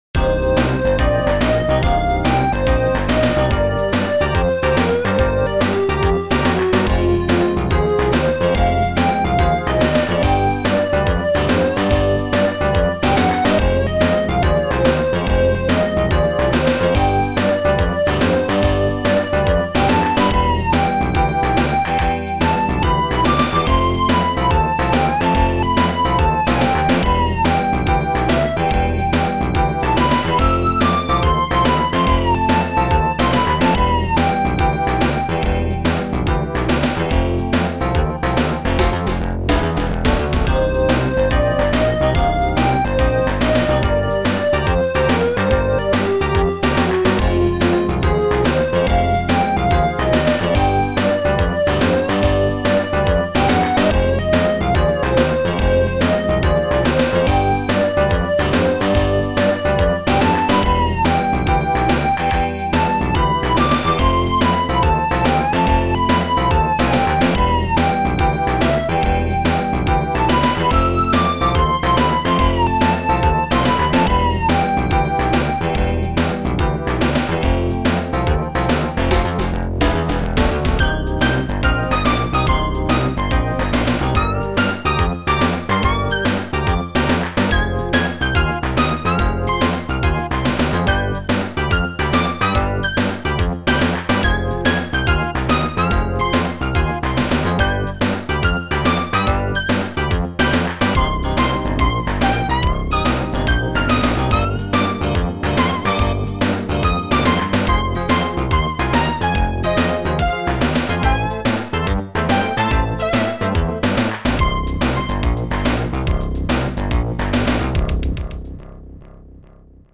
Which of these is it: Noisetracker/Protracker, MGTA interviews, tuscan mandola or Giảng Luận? Noisetracker/Protracker